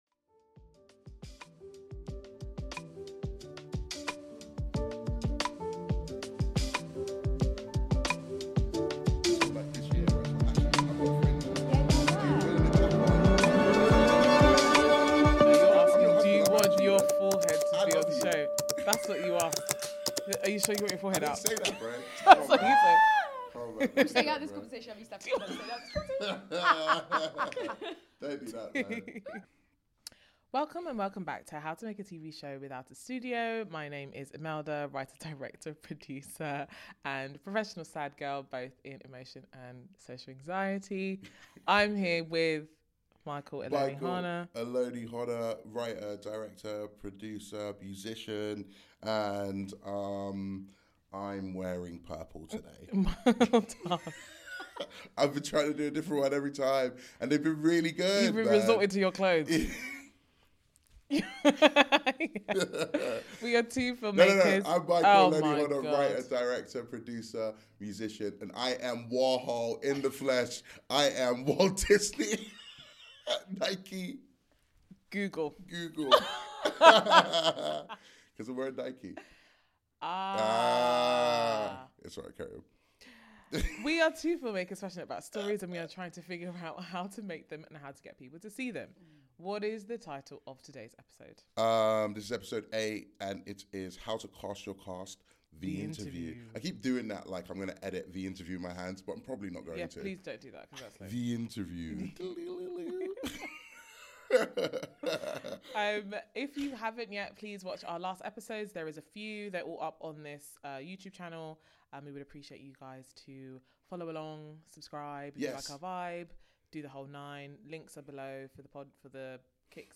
✨Episode 8: British actress Grace Saif joins us for a searching conversation about acting, identity and the structures shaping British film and theatre. We dig into colorblind casting, representation, class, and how economics and fear are stalling bold stories. Grace speaks about her Kenyan roots, voice work, the indie game world, and what real cultural storytelling could look like when artists own their platforms.